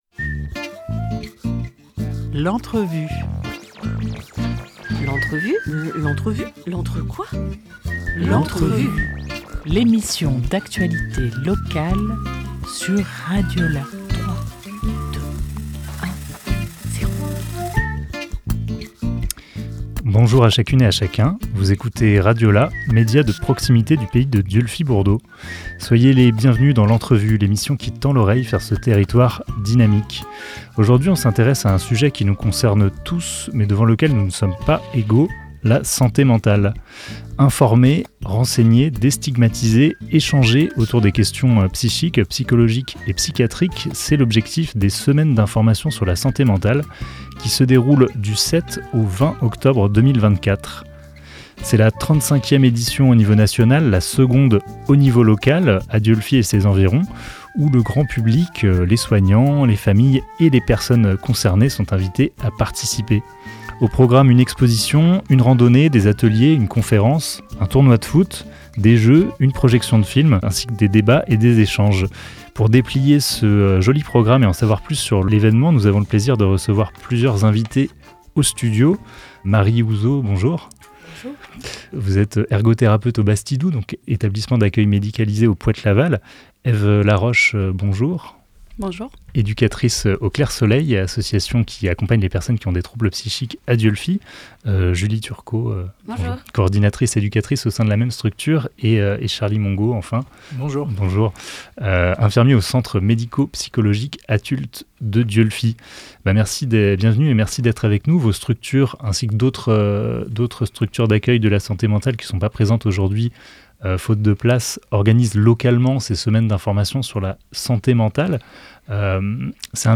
24 septembre 2024 12:28 | Interview